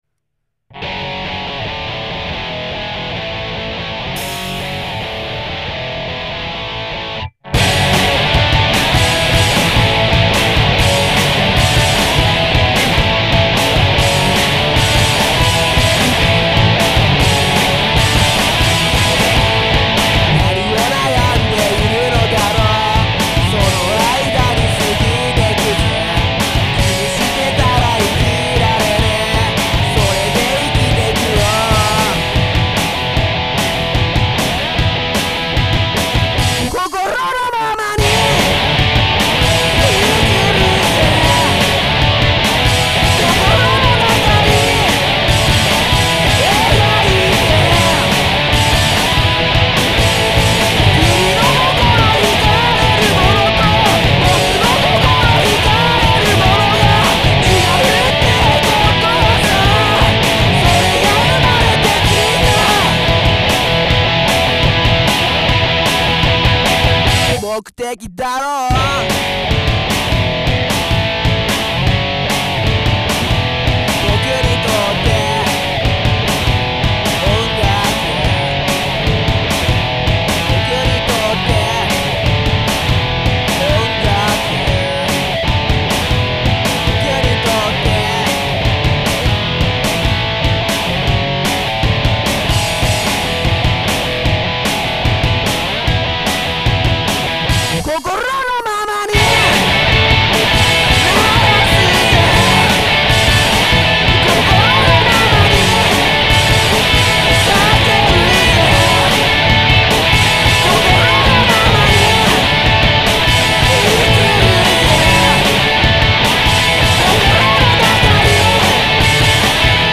POP ROCK
大学の頃から宅録で作ってきた曲を、